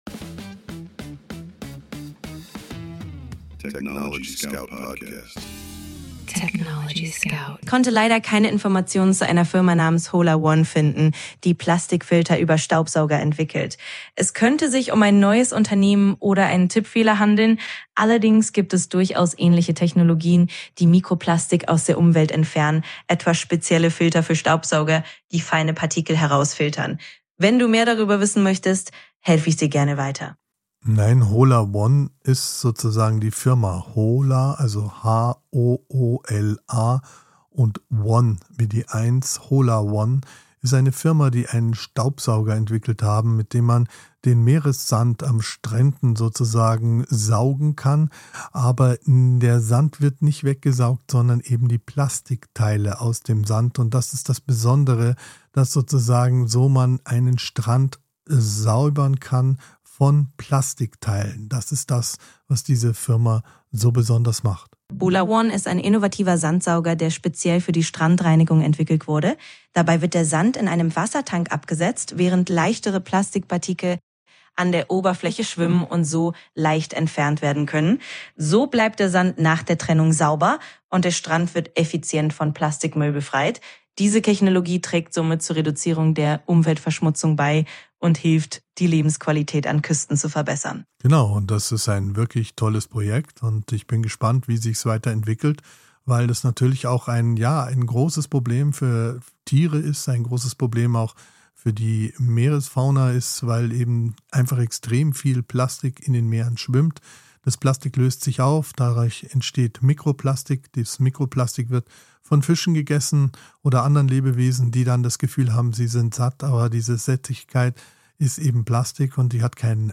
Mensch und KI sprechen miteinander – nicht